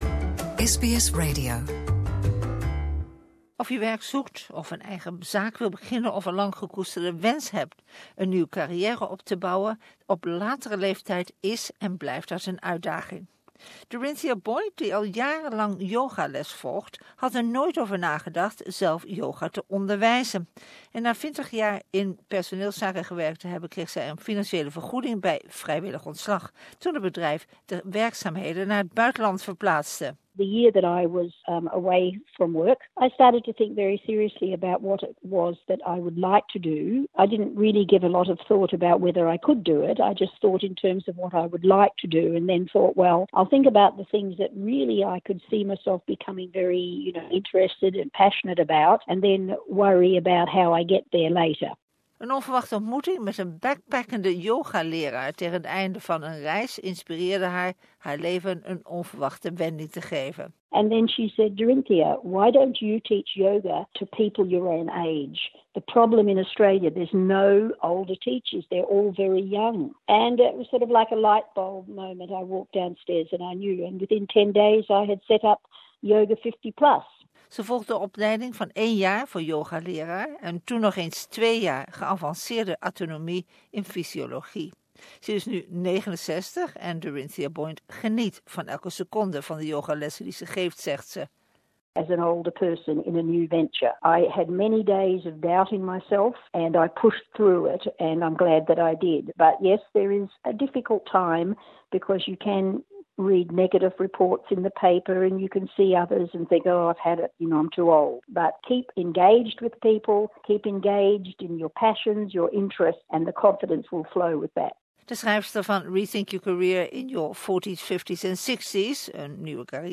Whether youre looking for work, want to start our own business or fulfil a long-term passion - starting a new career later in life can be challenging but worthwhile. In the first episode of VIVA we meet a woman who became a yoga teacher in her 60s, career coaches and an older worker recruiter for tips and trends to help you make a successful career transition.